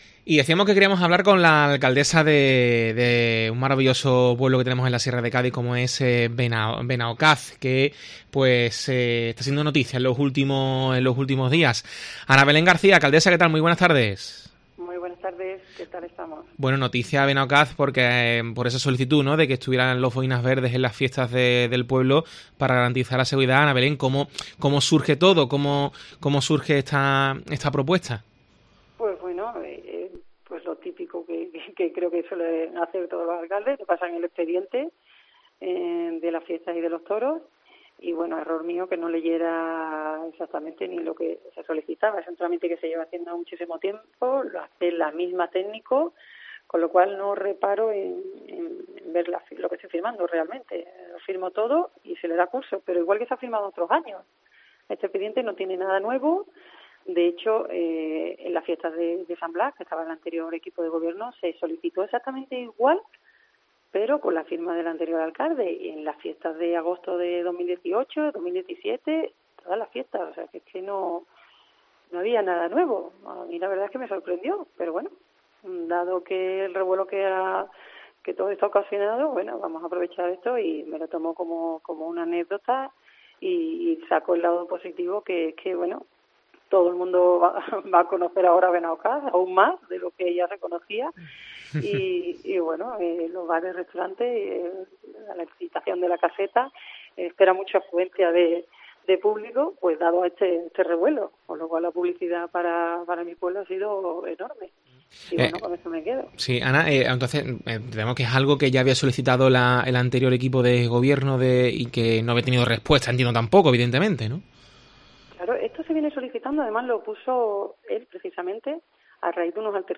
Ana Belén García, alcaldesa de Benaocaz